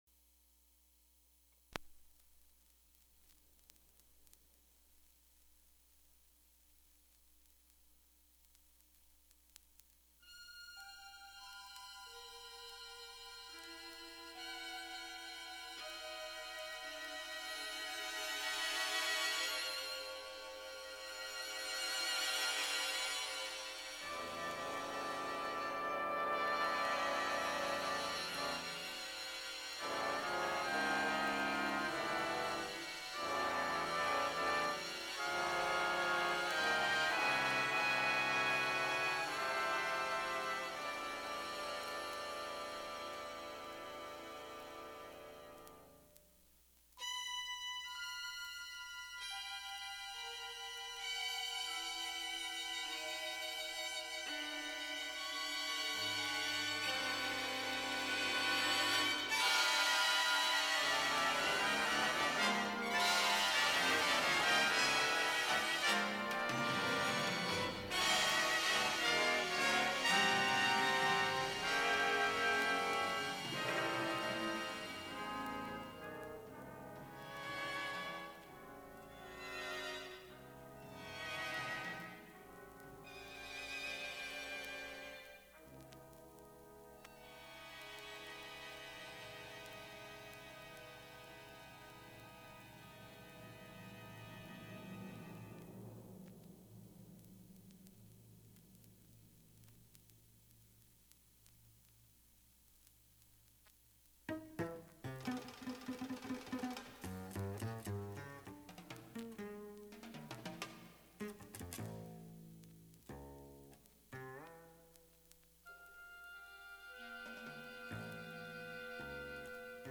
so here is a lo-fi transfer from the rare LP: